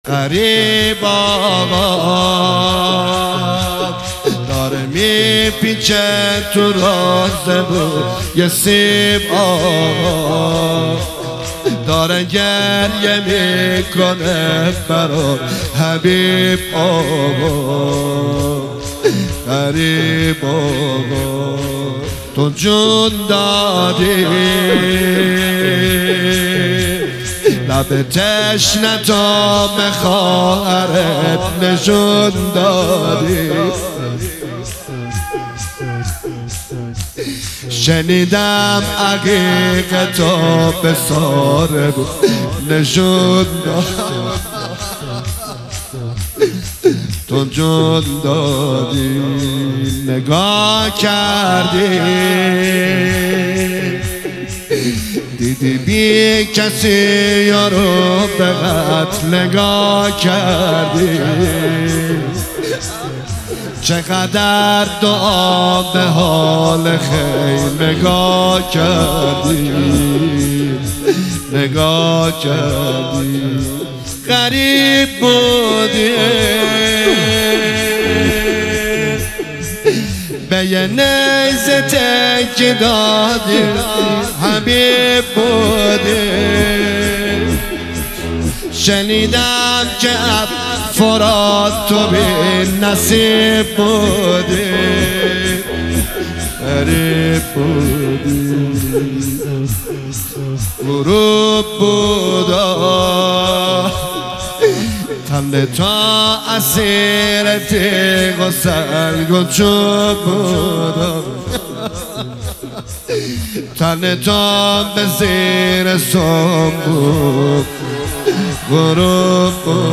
ایام فاطمیه 1399